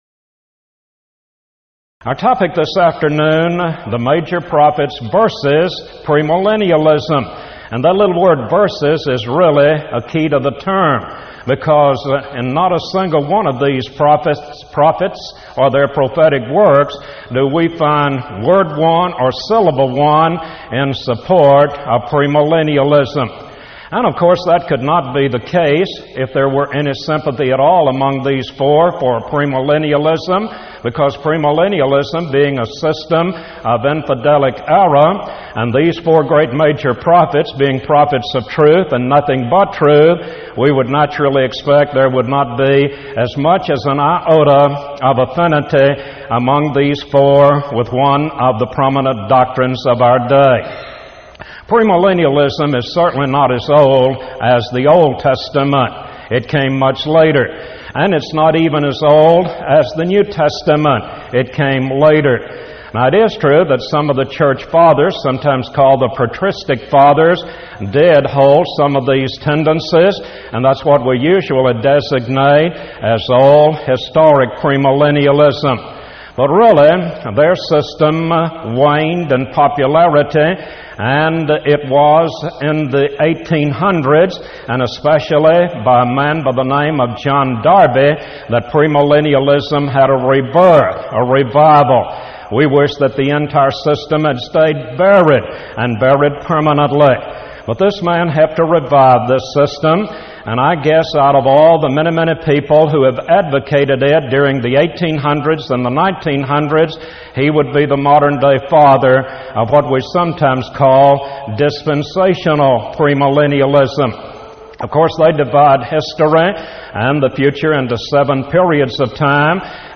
Event: 1995 Power Lectures
lecture